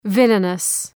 {‘vılənəs}
villainous.mp3